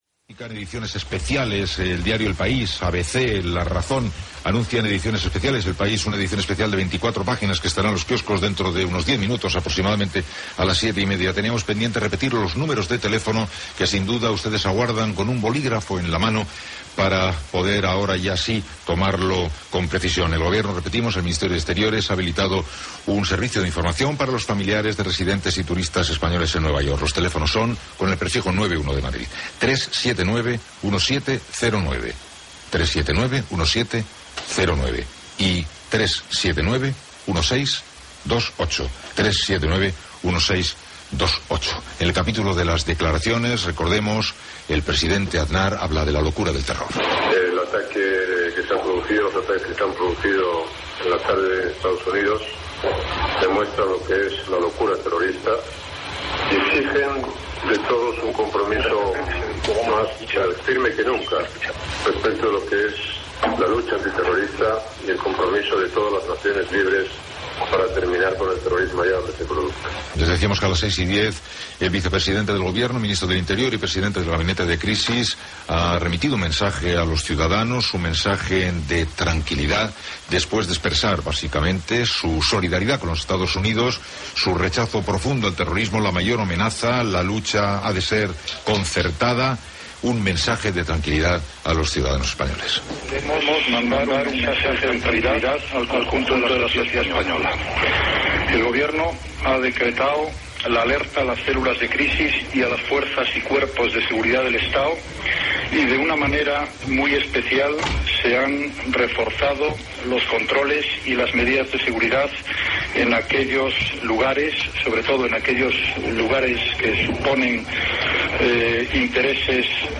Repercussió a les borses, entrevista al polític socialista Felipe González. Hora, publicitat, hora, edició especial dels diaris, comentari de la situació, comunicat de l'ambaixada d'EE.UU. a Espanya.
Gènere radiofònic Informatiu